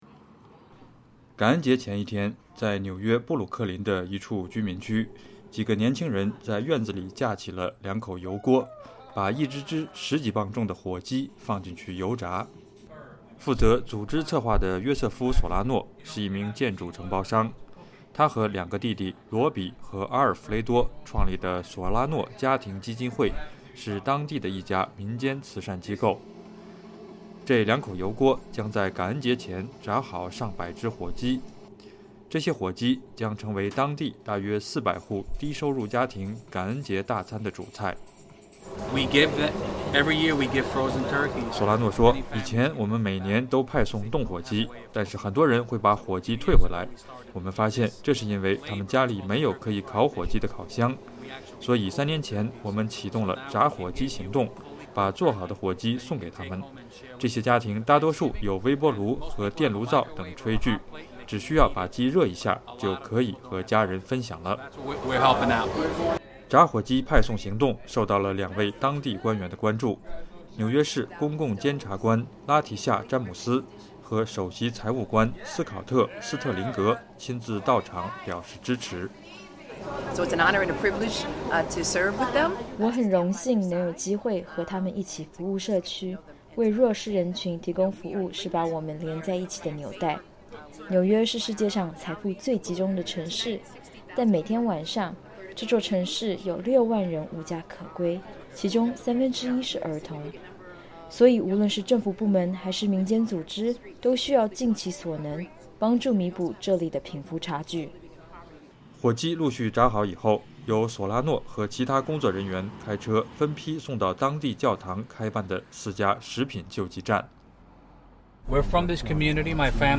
感恩节前一天，在纽约布鲁克林的一处居民区，几个年轻人在院子里架起两口油锅，把一只只十几磅重的火鸡放进去油炸。